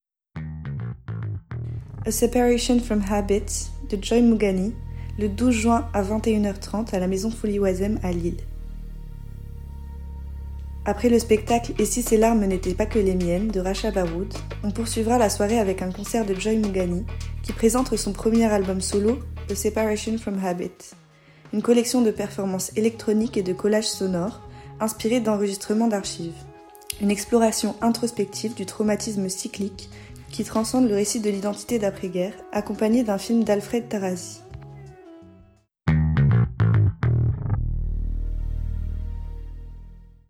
C’est de la musique électronique.